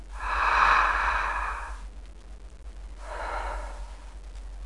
Deep Breath Sound Effect
Download a high-quality deep breath sound effect.
deep-breath.mp3